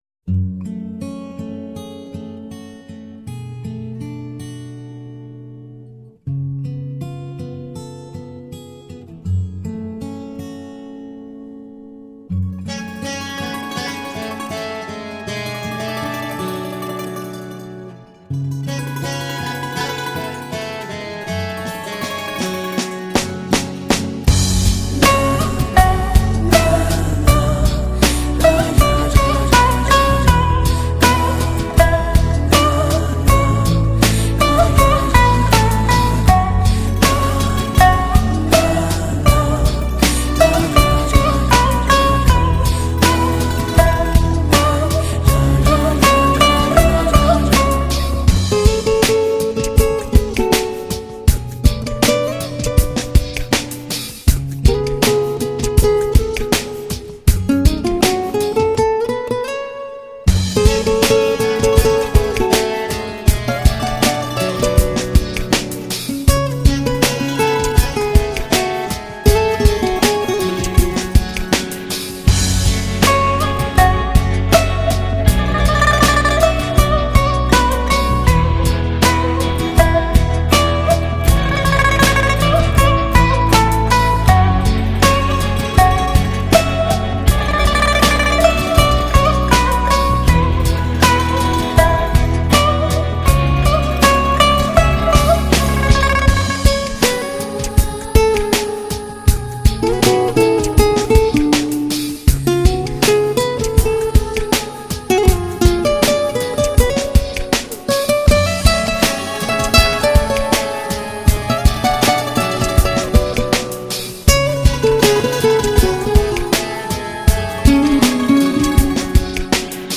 [14/11/2009]印度琵琶演奏 《四叶草》 激动社区，陪你一起慢慢变老！